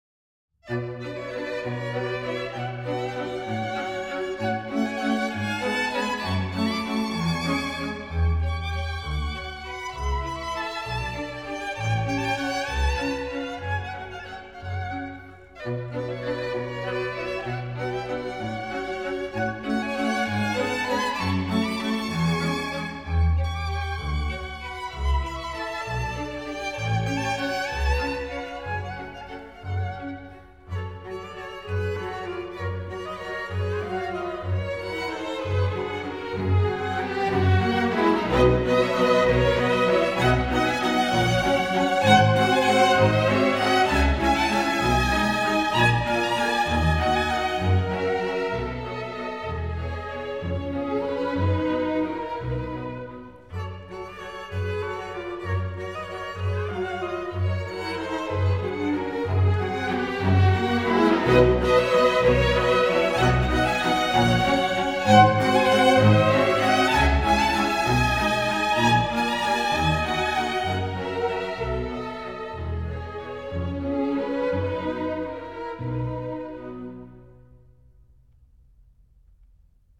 Johannes Brahms: Waltz no. 11 in B minor.
Camerata Bern